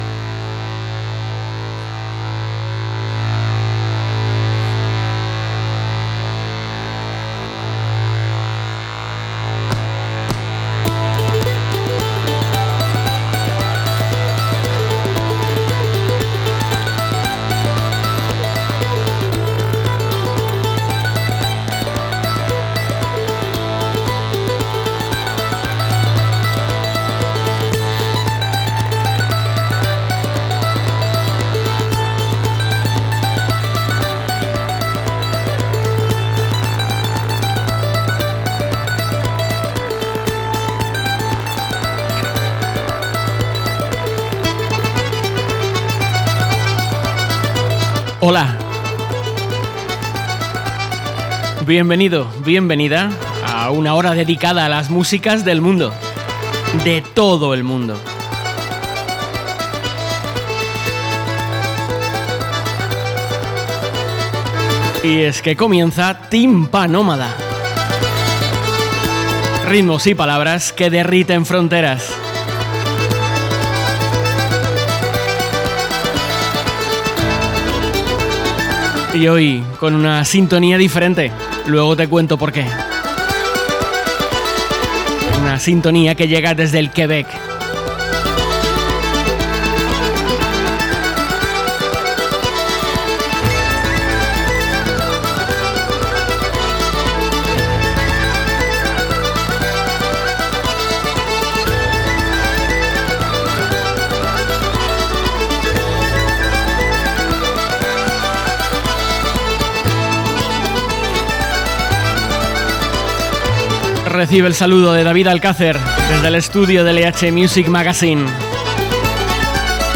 Nos visita la banda de México Velazquez para hablarnos de su gira por España y de sus futuros proyectos. En su música puedes encontrar Rock, Folk, Country y la Música Tradicional Mexicana… pero han conseguido que cuando les escuchas sabes que son Velazquez.